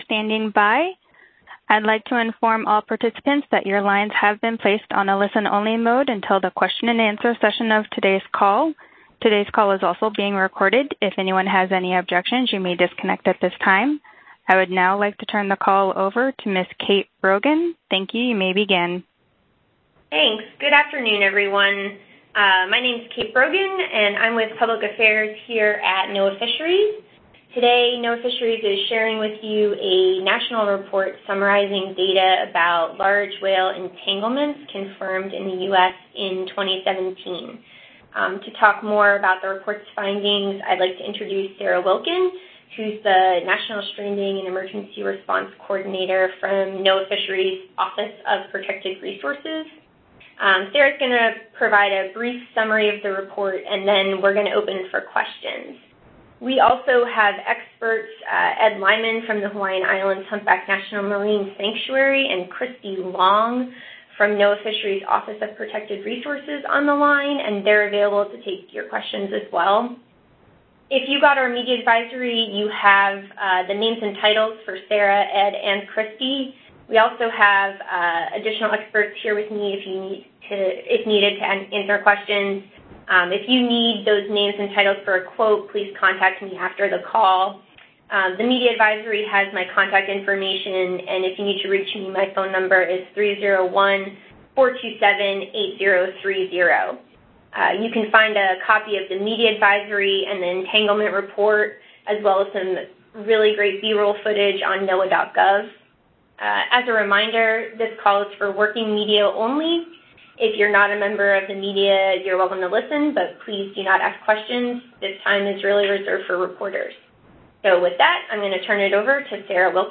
AUDIO - National Large Whale Entanglement Report Call Dec 6.mp3